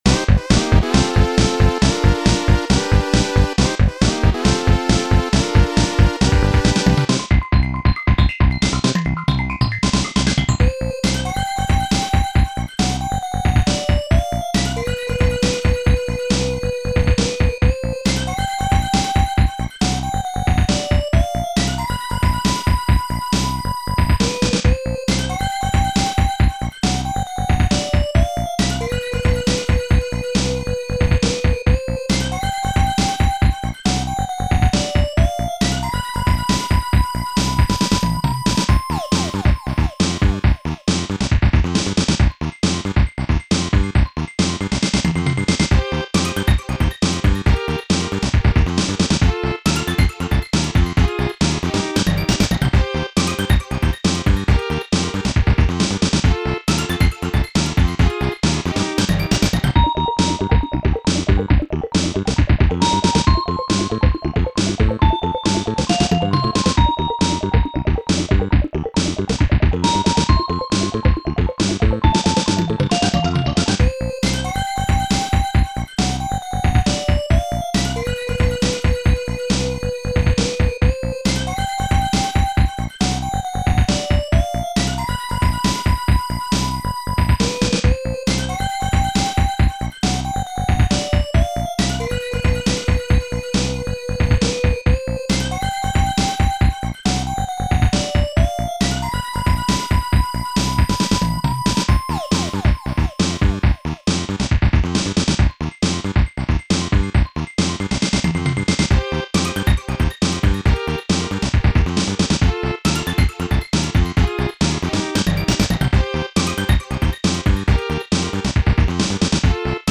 bizarre opening and distorted notes